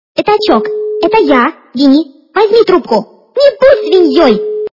» Звуки » Из фильмов и телепередач » Пятачок! - Это я Винни!
При прослушивании Пятачок! - Это я Винни! качество понижено и присутствуют гудки.